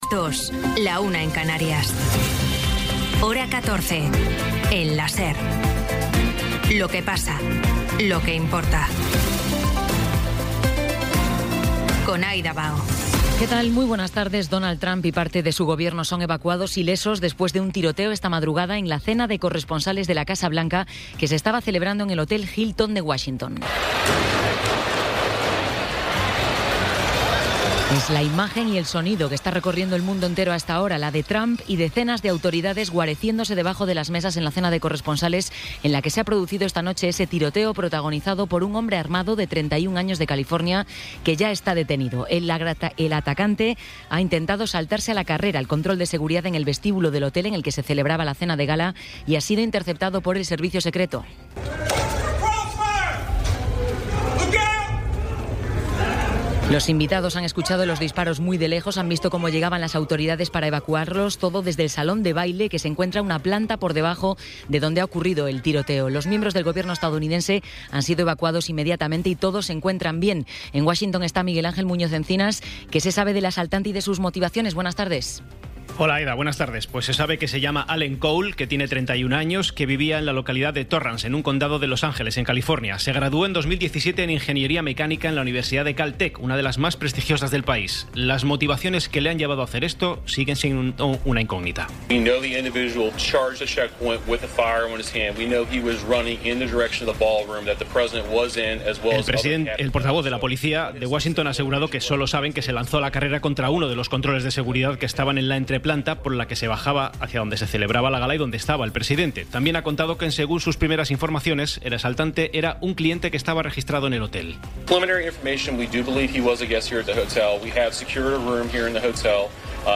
Resumen informativo con las noticias más destacadas del 26 de abril de 2026 a las dos de la tarde.